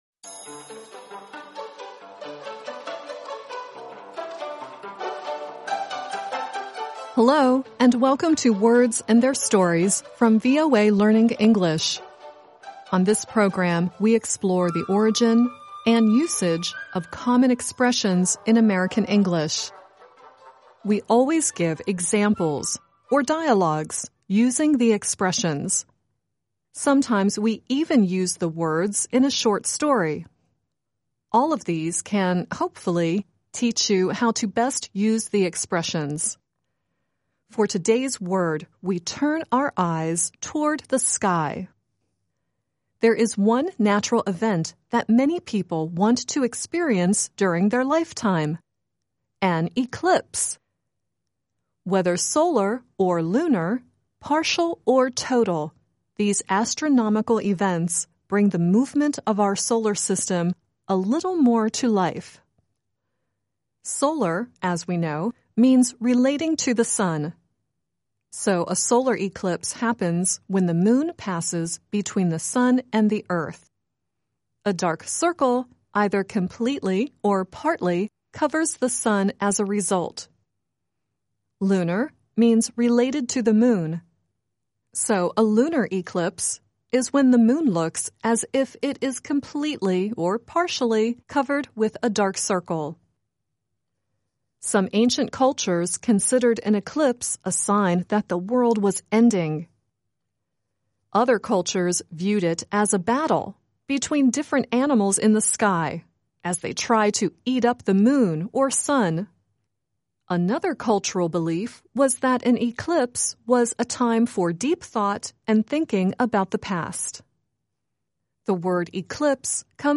The song at the end is Bonnie Tyler singing "Total Eclipse of the Heart."